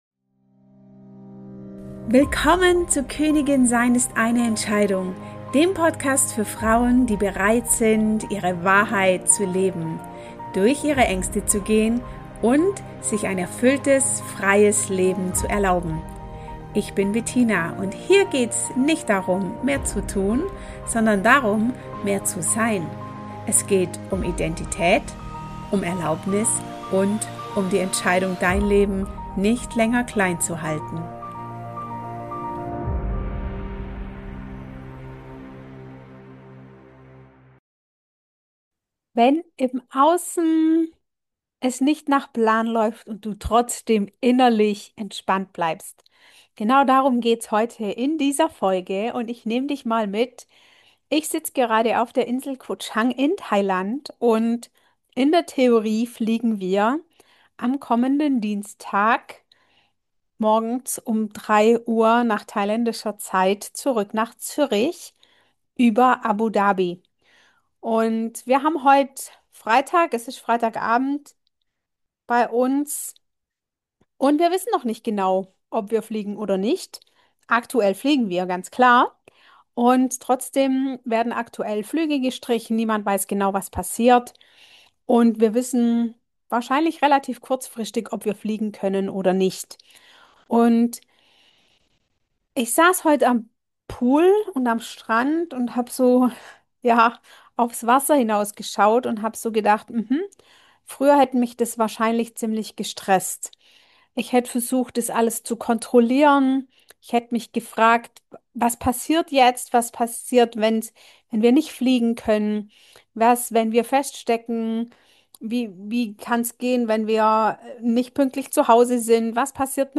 Beschreibung vor 2 Tagen In dieser Folge nehme ich dich mit auf eine Reise mitten ins Ungewisse – direkt von einer thailändischen Insel, während unsere Rückreise plötzlich auf der Kippe steht.